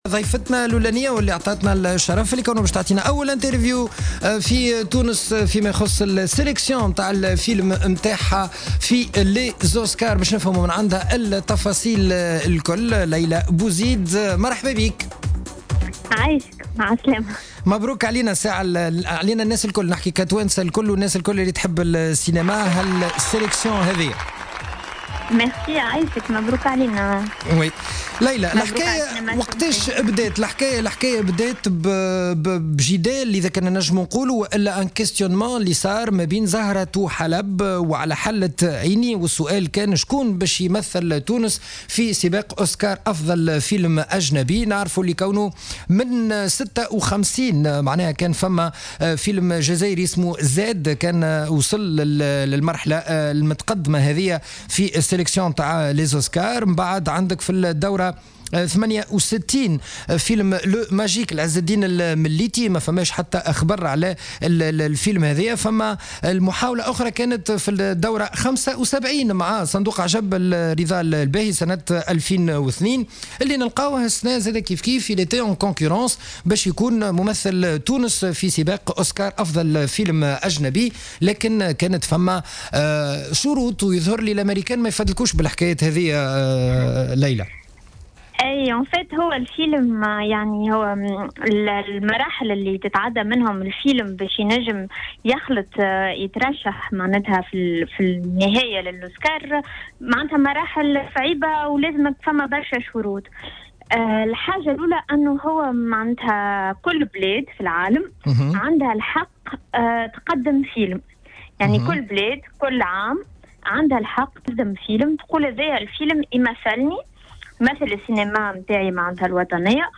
في حوار حصري مع الجوهرة أف أم، خلال حصة "سينما سينما" تحدثت المخرجة ليلى بوزيد عن فيلمها "على حلة عيني" الذي سيمثل تونس في الدورة 89 لجوائز الأوسكار التي ستلتئم في 26 فيفري 2017 في لوس أنجلس.